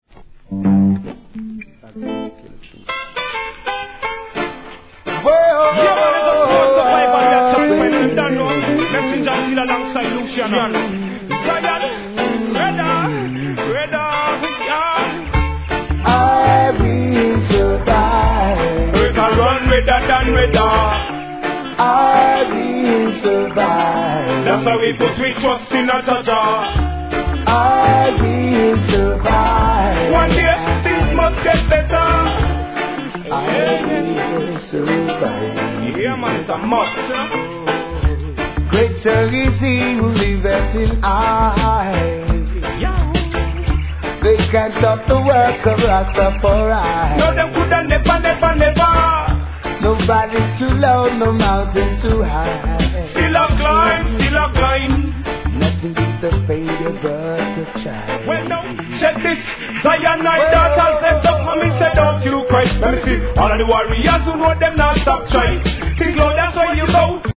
REGGAE
哀愁ミディアムで素晴らしいコンビ物!